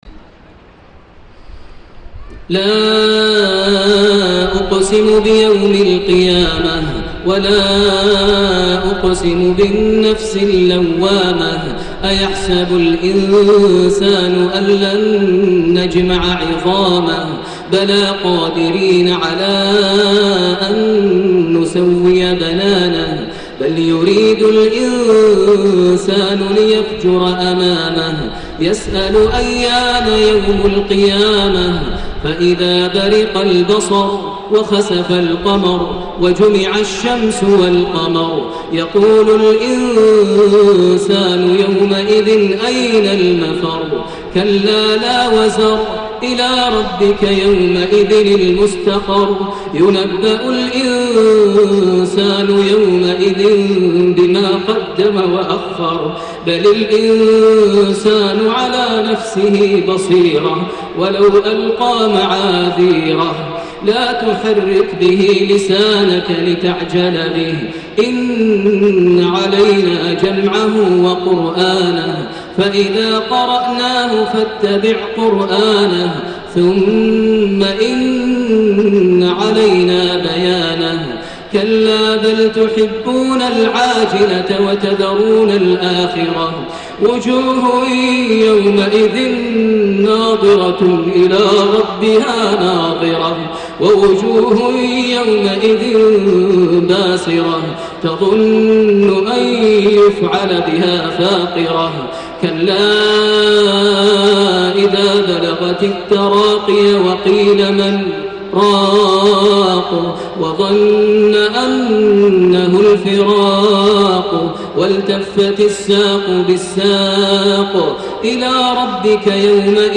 صلاة الخسوف ١٤ محرم ١٤٣١هـ سورة القيامة > صلاة الخسوف > المزيد - تلاوات ماهر المعيقلي